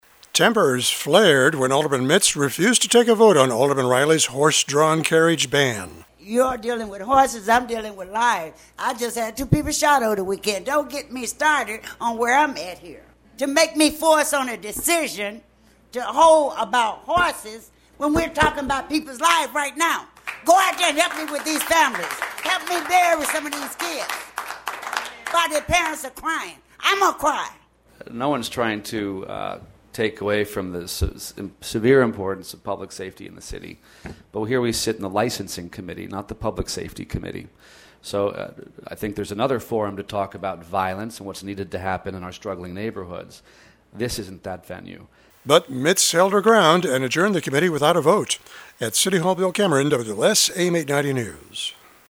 (CHICAGO)  At City Hall this afternoon, a four-hour-long city council hearing on banning horse-drawn carriages degenerated into an emotional clash about Chicago street violence between chairperson Ald Emma Mitts and downtown Ald Brendan Reilly.